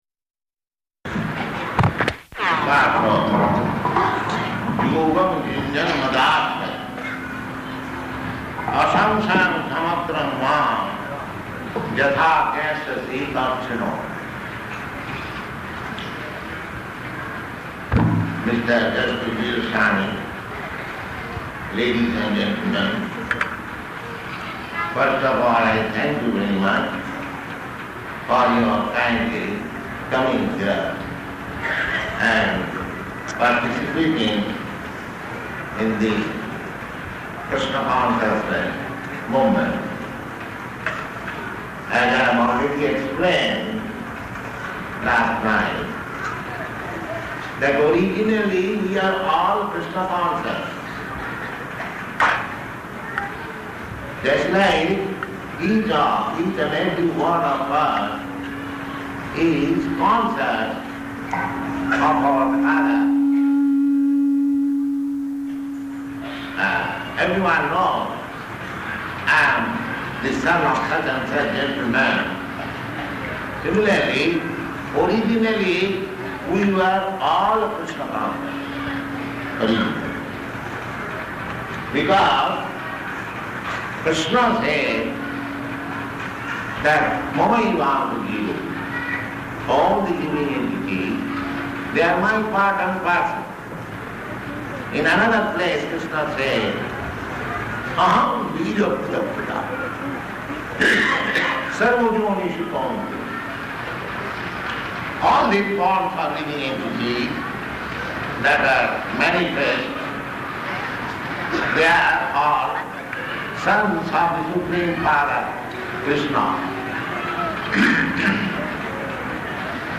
Location: Madras